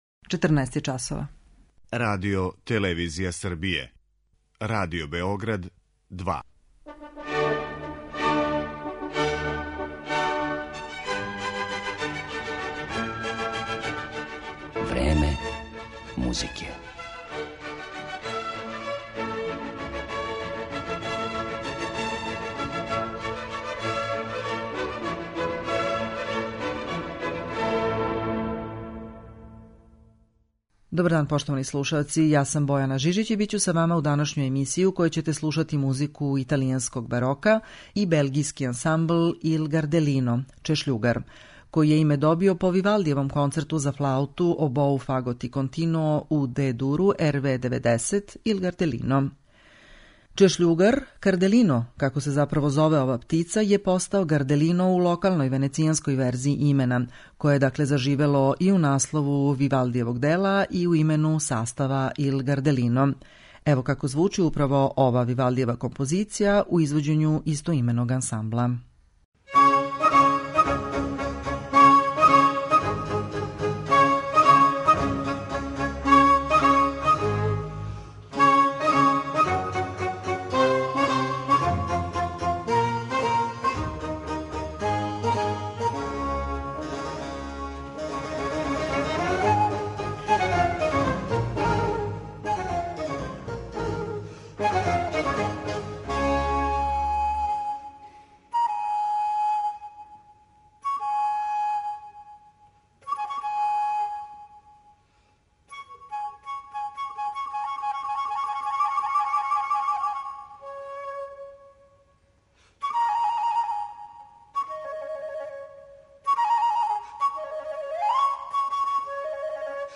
Ансамбл Il Gardellino
По Вивалдијевом концерту за флауту, обоу, фагот и континуо у Дe-дуру, Il Gardellino (Чешљугар), добио је име изврсни белгијски ансамбл за рану музику, који на веома вешт начин мири историјску веродостојност и жељу да звучи модерно.